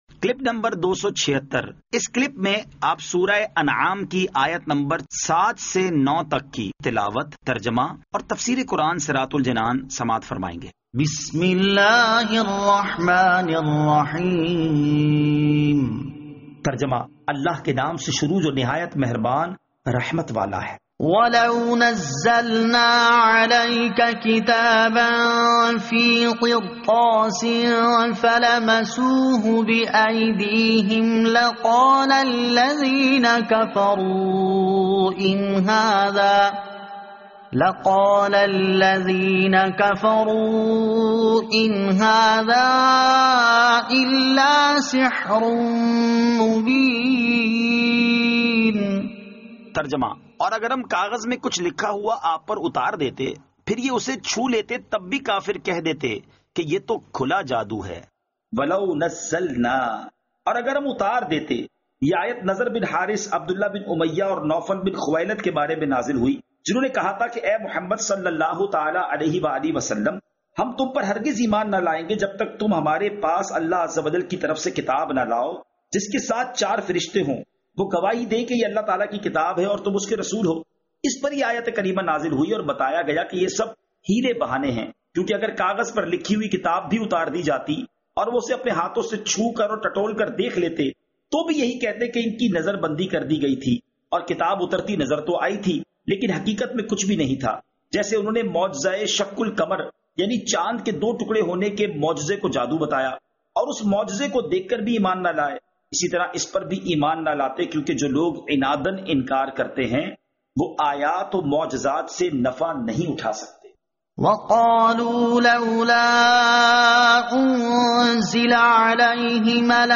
Surah Al-Anaam Ayat 07 To 09 Tilawat , Tarjama , Tafseer